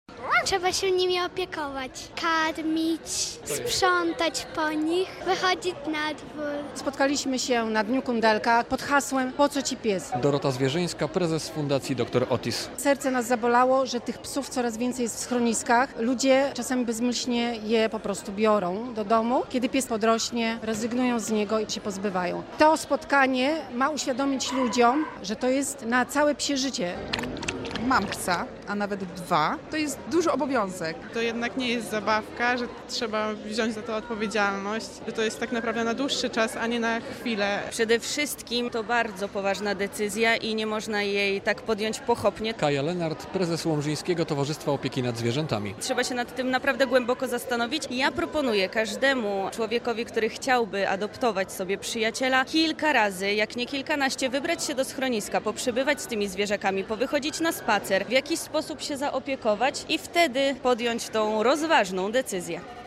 Tego uczą członkowie Fundacji Dr Otis i przedstawiciele łomżyńskiego schroniska dla zwierząt w sobotę (18.10) podczas Dnia Kundelka w Galerii Veneda w Łomży.